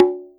BONGO-CONGA114.wav